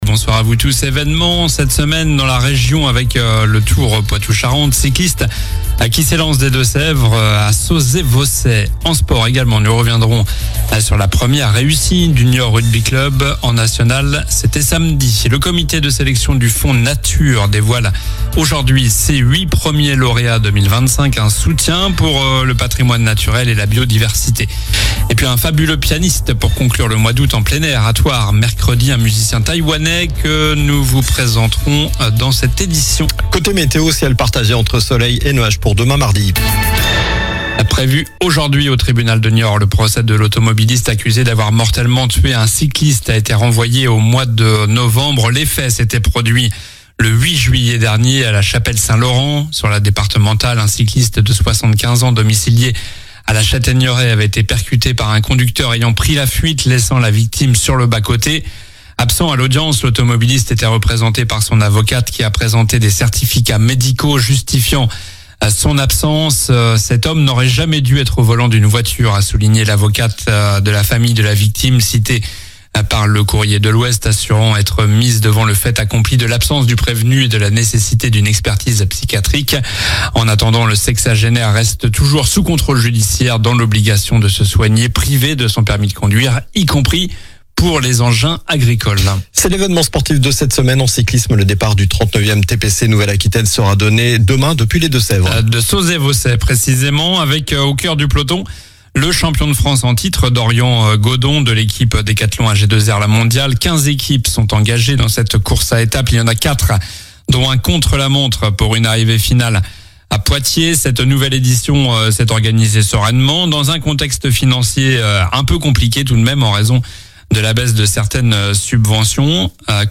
Journal du lundi 25 août (soir)